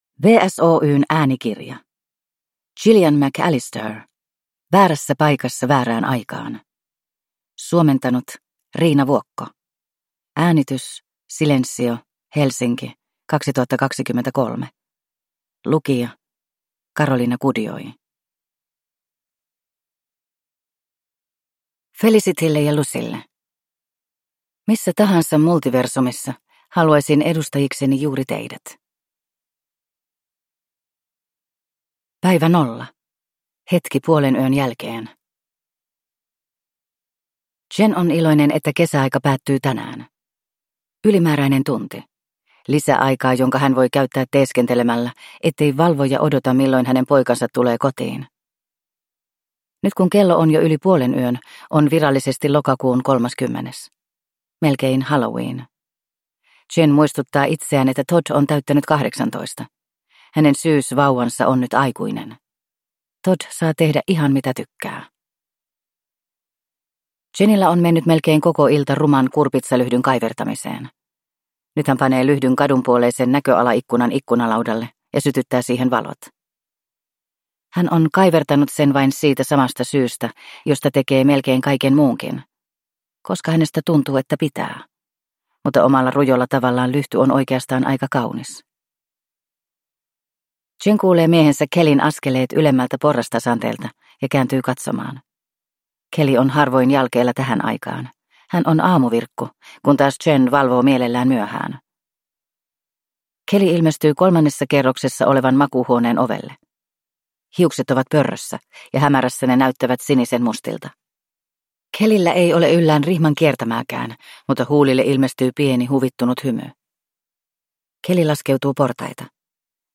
Väärässä paikassa väärään aikaan – Ljudbok – Laddas ner